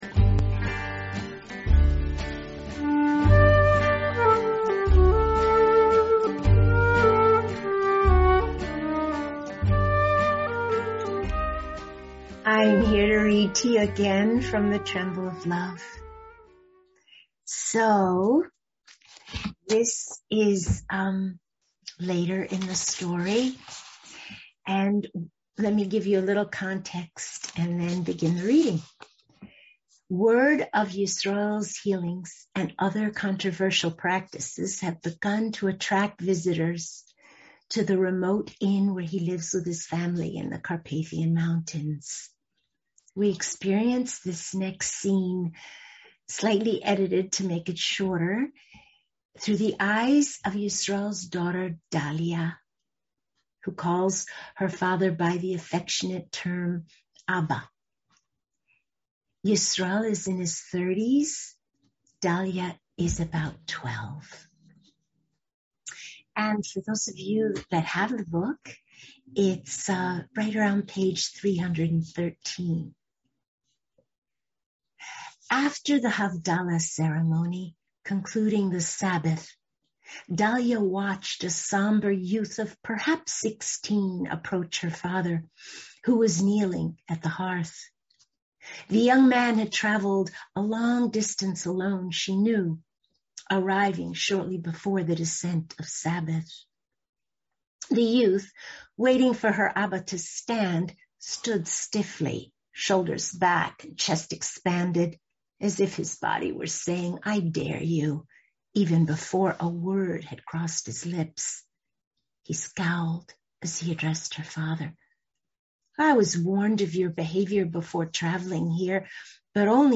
Suddenly, this reading from The Tremble of Love seemed the perfect one to share.
Note: The reading and its text shared below have been slightly edited to make them shorter.